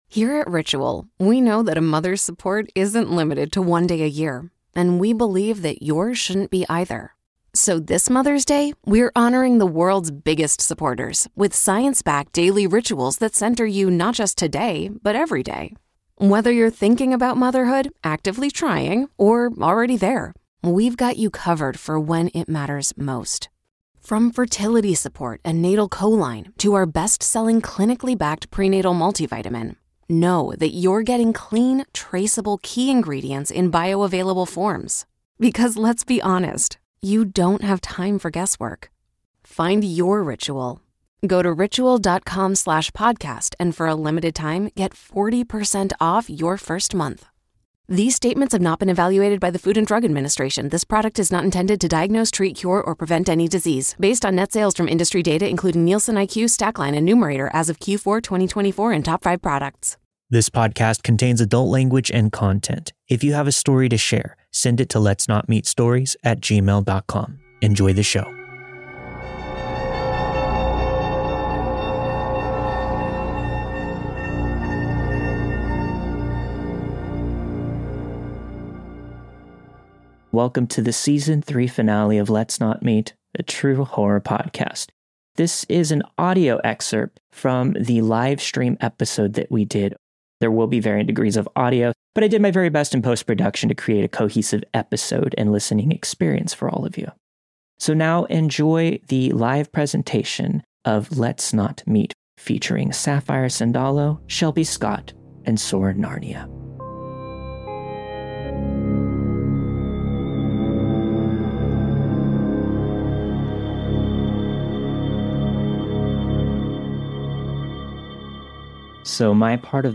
Stories in this live stream episode: